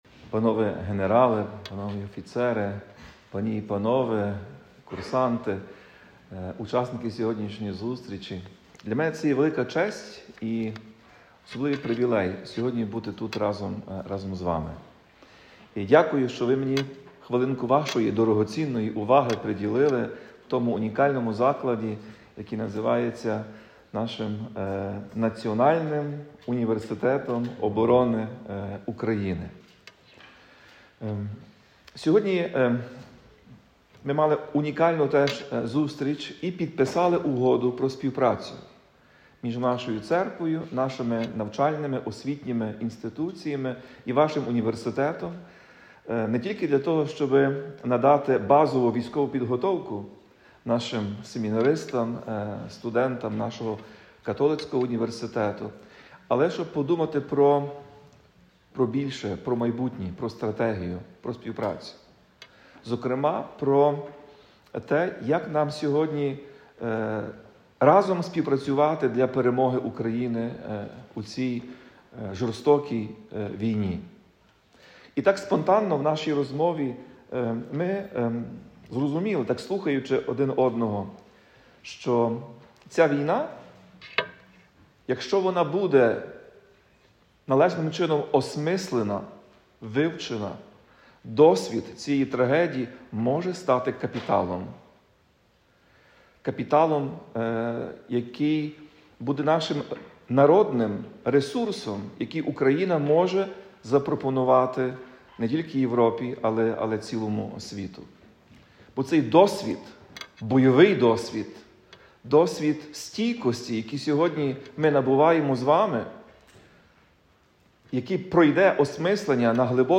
Слово Блаженнішого Святослава в Національному університеті оборони України 23 січня 2026 року.